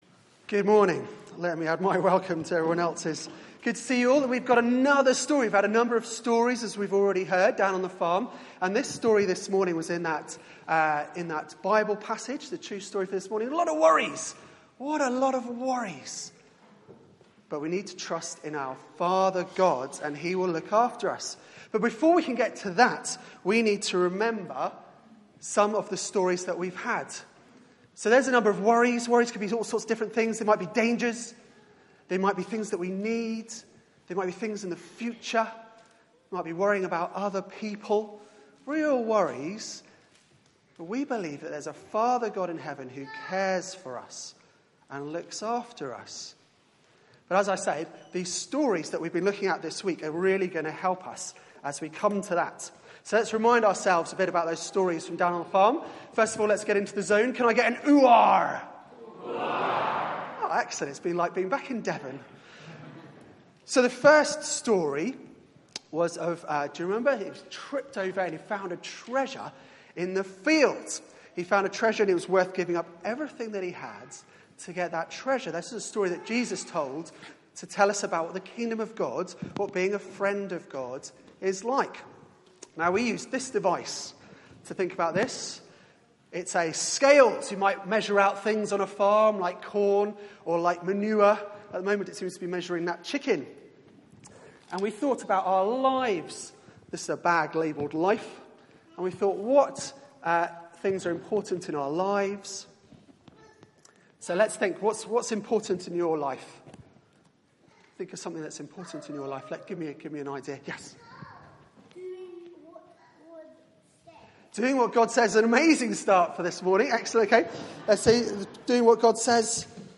Media for 9:15am Service on Sun 28th Aug 2016 11:00 Speaker
Sermon